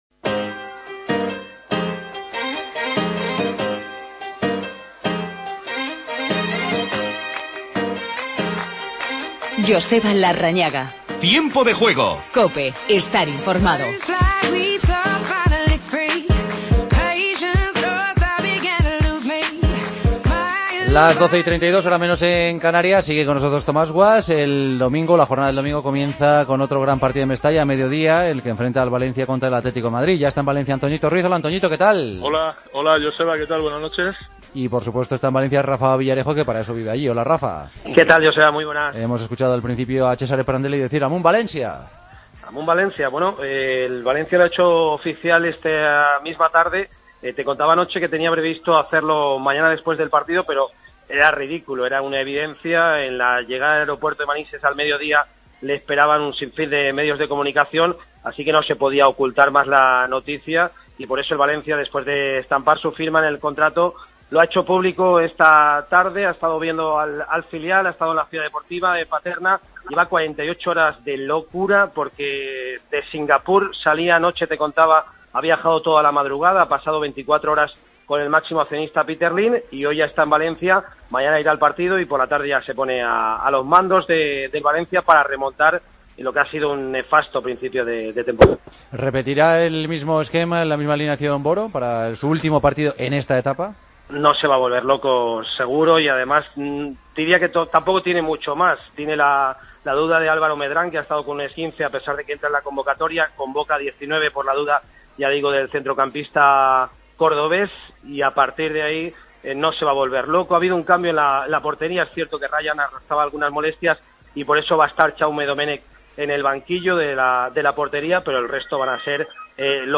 Previa del Valencia-Atlético de Madrid. Los ches hacen oficial el fichaje de Prandelli. Entrevista a Szymanowski, autor del gol de la victoria del Leganés en Granada. Analizamos la victoria del Sevilla con doblete de Ben Yeeder.